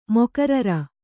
♪ mokarara